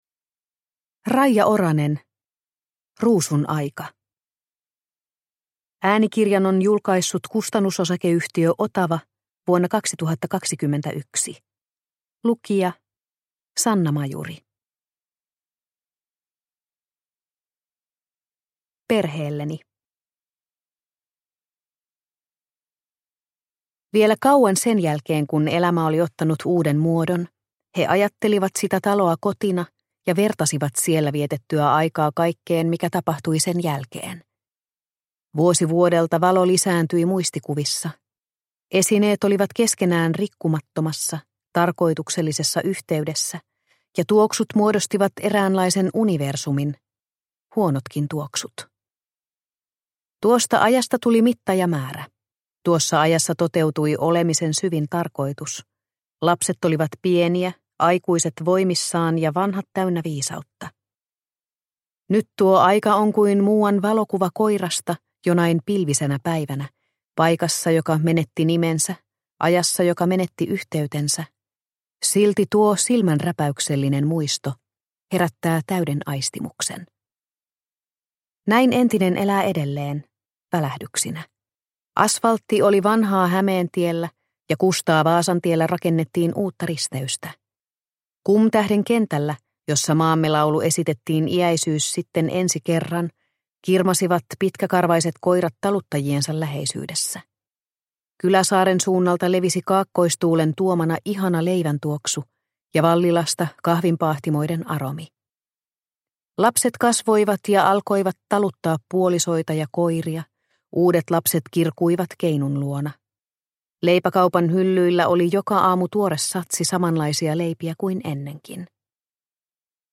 Ruusun aika – Ljudbok – Laddas ner